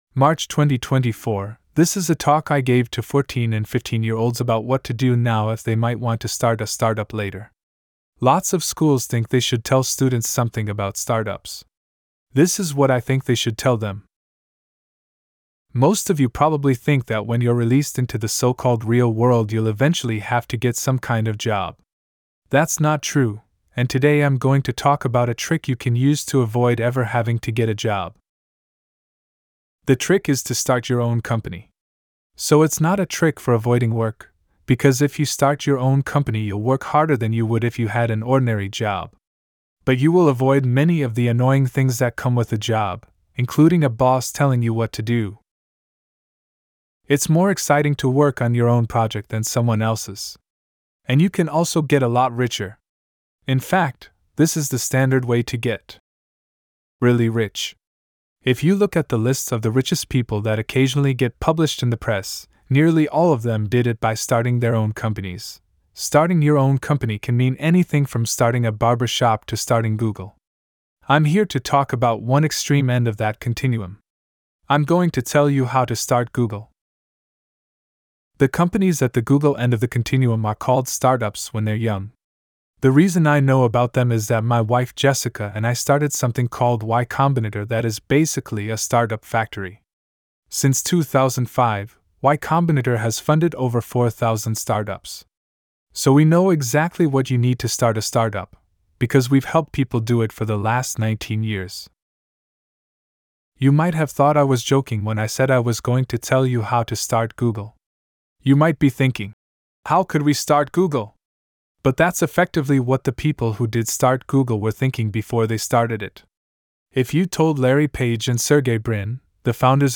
If you want to listen to the generated audio (really it just custom parses a web page and sends it off to azure), I've uploaded it to a public Cloudflare R2 bucket[1]. It's not perfect, I haven't fully configured the SSML to treat quotes correct, and I'm adding weird breaks in between normal text and anchors, but... it's a project :).